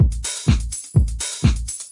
描述：Tech Loop 125 bpm
标签： Minimal Electronic 125BPM Loop Techno Dub
声道立体声